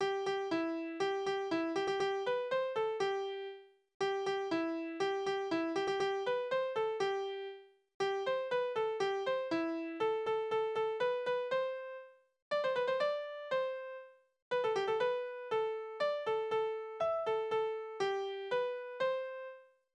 Tierverse: Schmetterling
Tonart: C-Dur
Taktart: 2/4
Tonumfang: Oktave